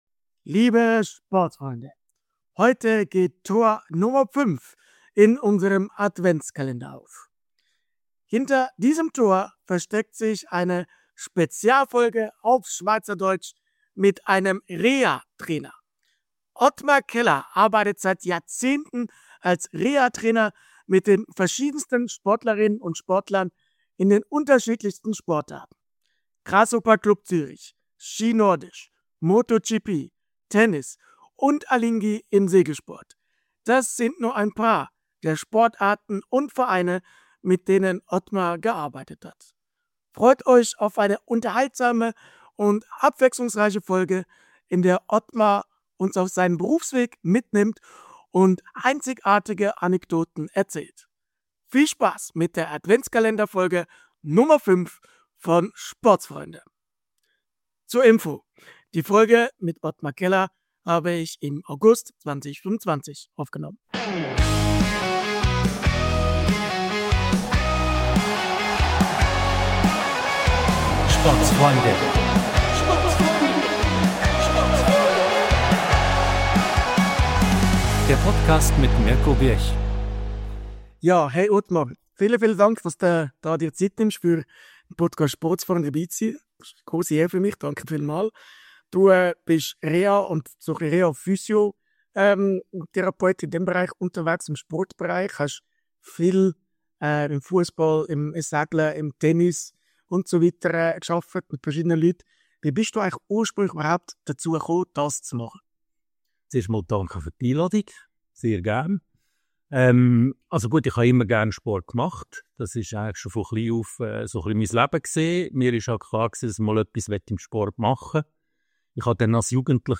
Spezialfolge auf Schweizerdeutsch! ~ Mixed-Sport Podcast